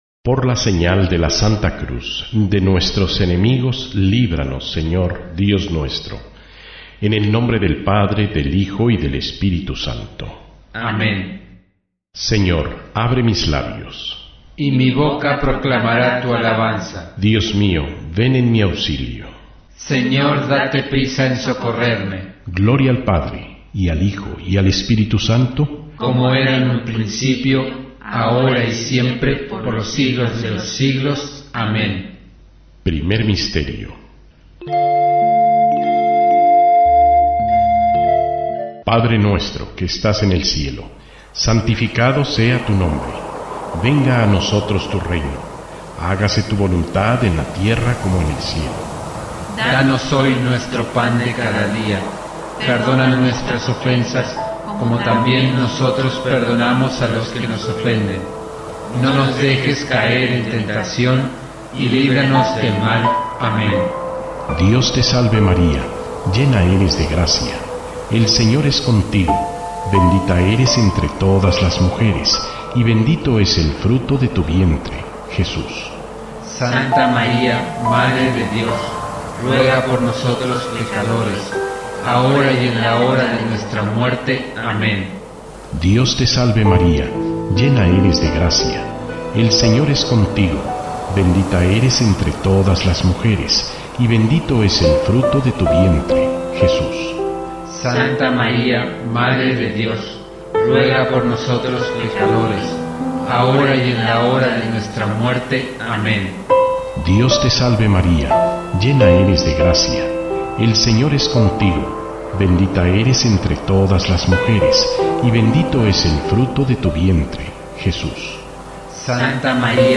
DESCARGA, el rosario en MP3 cortes�a de [AVM RADIO].
El rosario no se debe entender como una oraci�n para desgartarse mentalmente, al contrario es fundamentalmente una oraci�n de tipo contemplativa por lo que esta forma de rezarlo, escuch�ndolo como m�sica de fondo puede facilitar ese tono contemplativo.